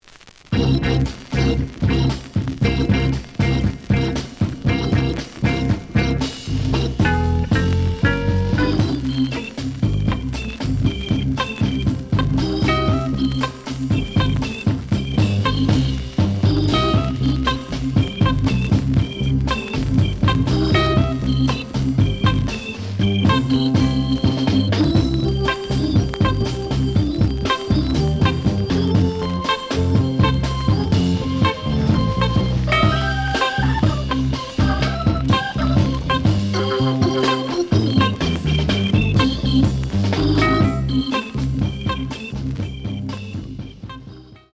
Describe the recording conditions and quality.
The audio sample is recorded from the actual item. Some noise on both sides.